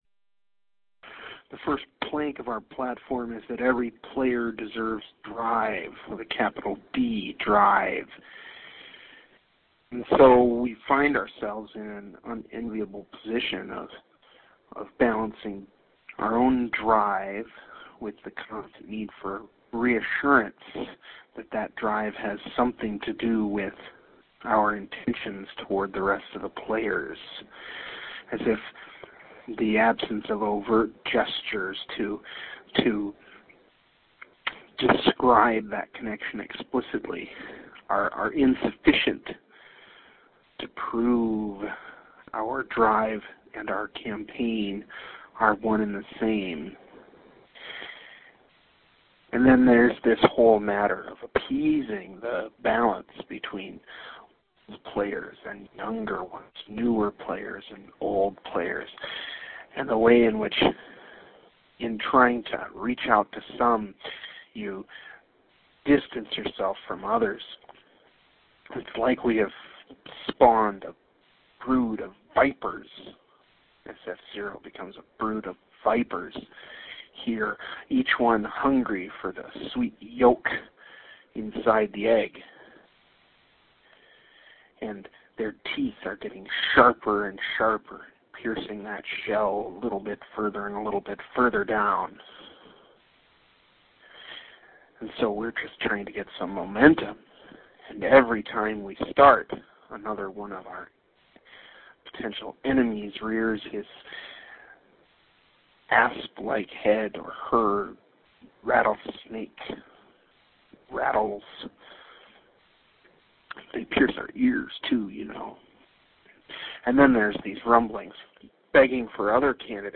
Speech May 2 part A